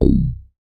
MoogHiVoc 003.WAV